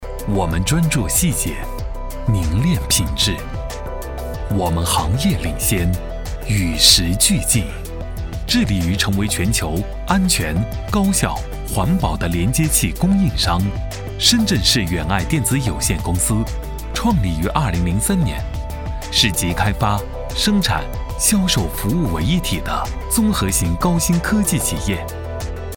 电子科技配音男125号（年
年轻时尚 产品解说
年轻质感男音，擅长旁白解说、电子科技感配音、企业宣传片解说等题材，作品：电子科技宣传片解说。